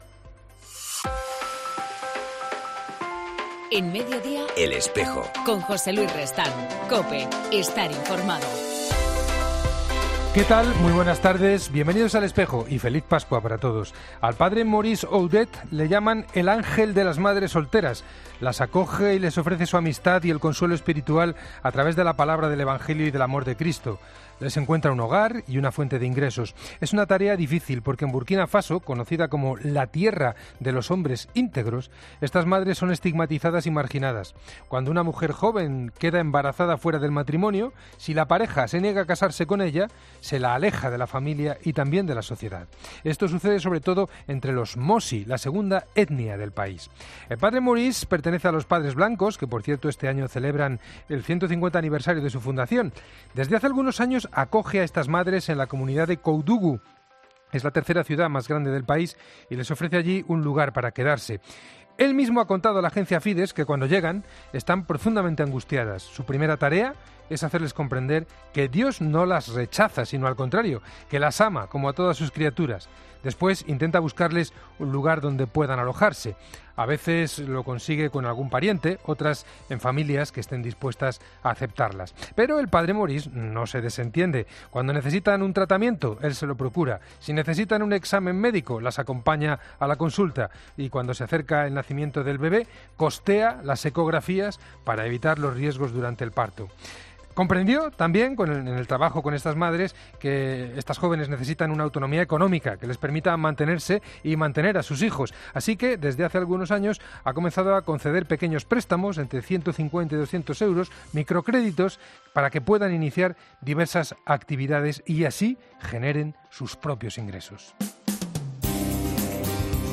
En El Espejo del 2 abril entrevistamos a Miguel Ángel Sebastián, obispo de la Diócesis de Laï, en Chad